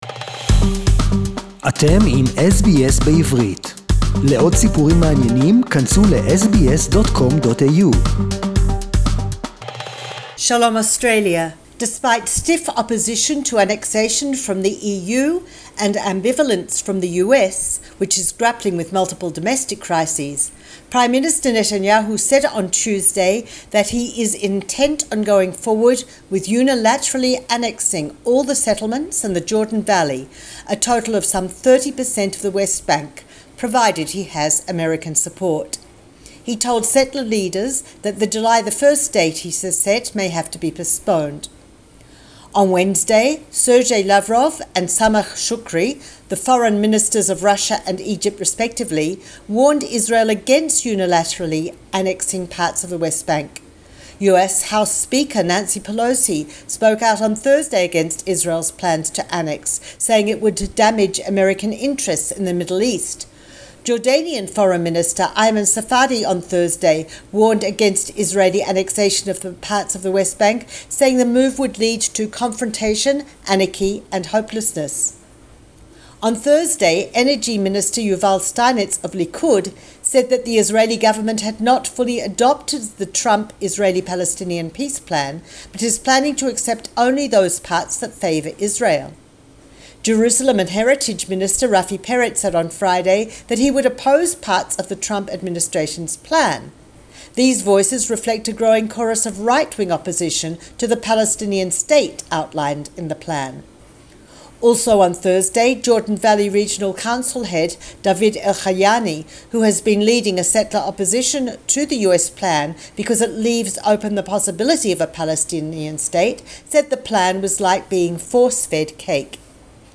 Israeli gov. plans to annex the Jordan Valley...SBS Jerusalem report in English